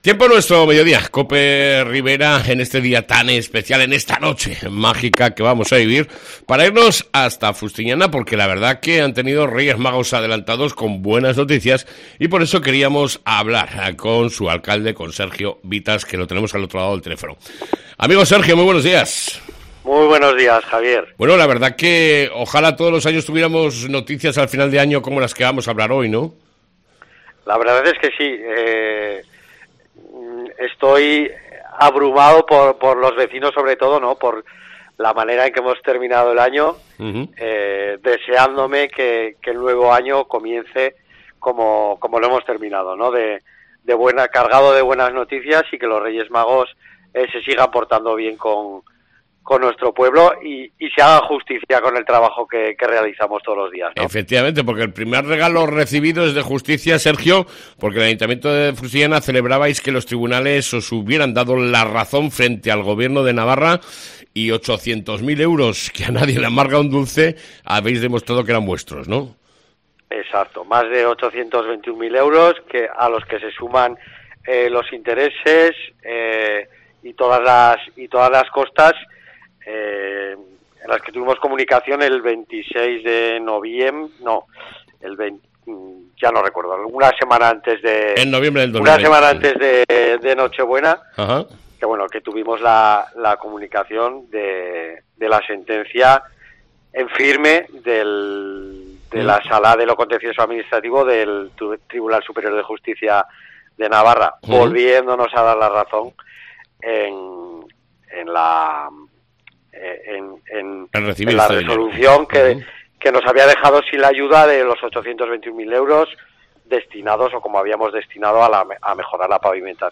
ENTREVISTA CON SERGIO VITAS, ALCALDE DE FUSTIÑANA